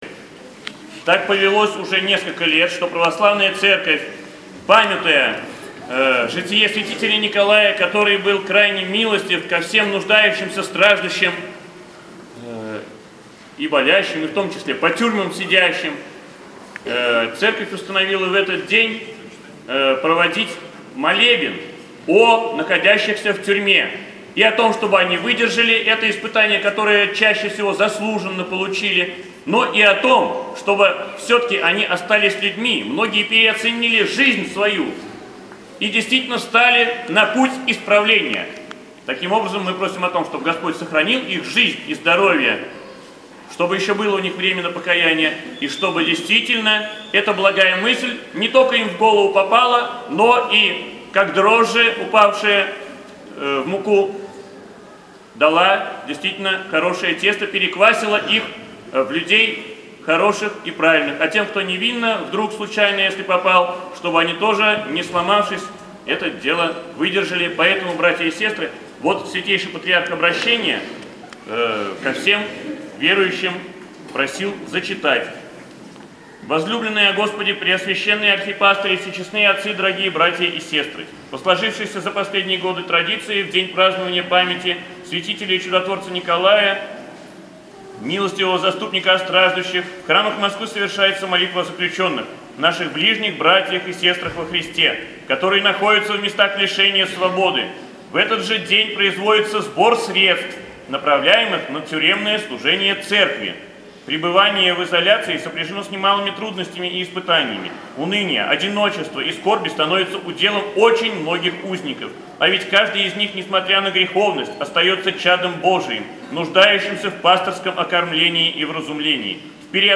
НОВОСТИ, Проповеди и лекции